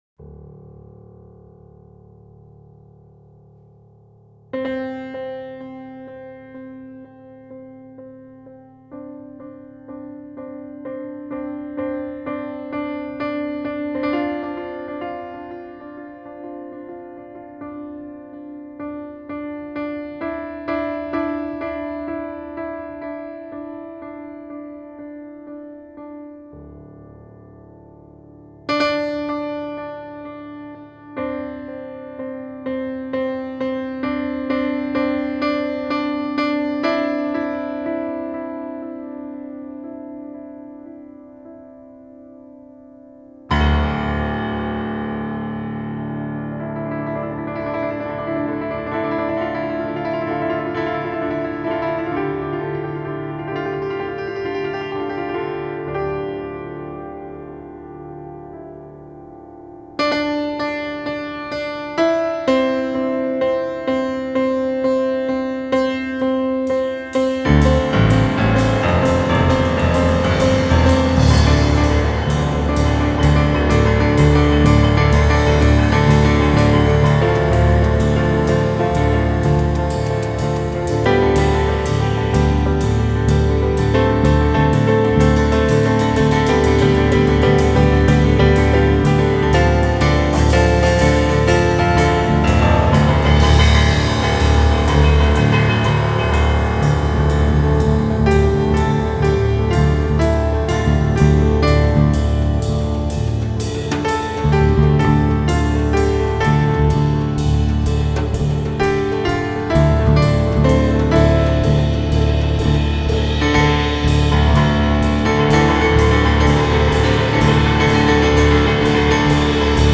piano
fretless electric bass
drums/percussion